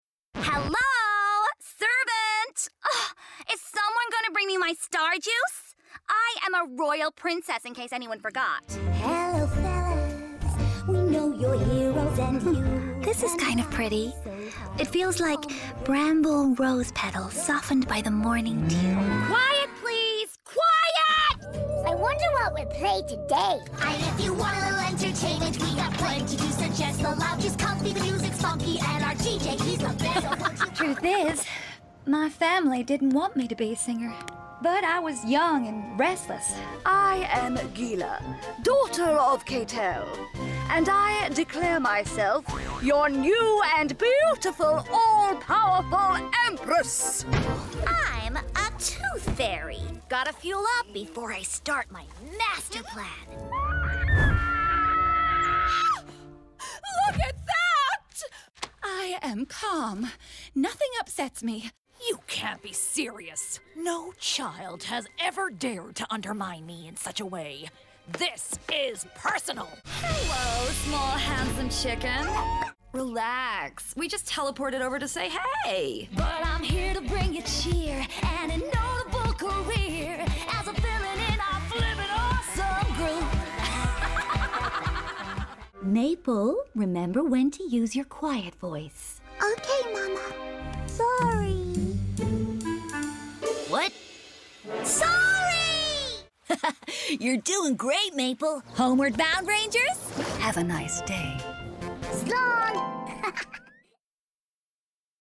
Animation/Character
Irish, American, Standard English/RP, London/Cockney, Northern (English), French
Actors/Actresses, Character/Animation, Comedy, Corporate/Informative, Husky/Seductive/Sexy, Modern/Youthful/Contemporary, Natural/Fresh, Quirky/Interesting/Unique, Smooth/Soft-Sell, Understated/Low Key, Upbeat/Energy